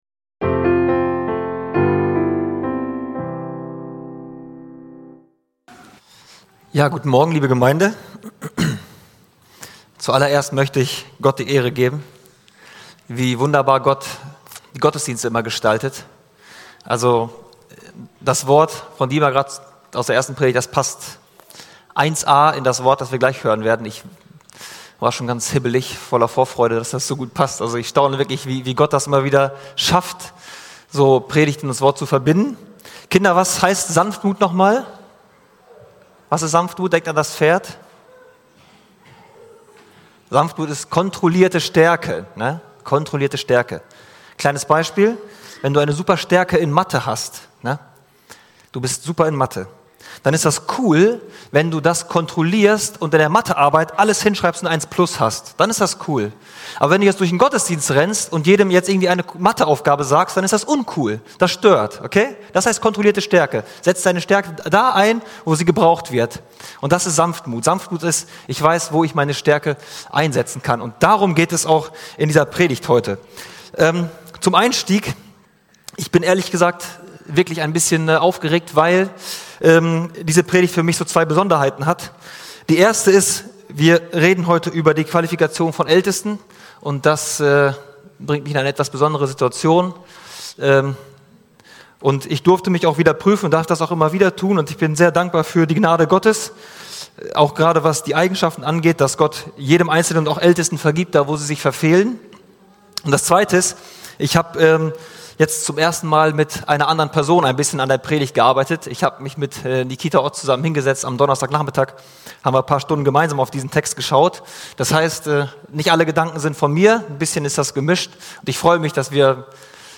Hauptpredigt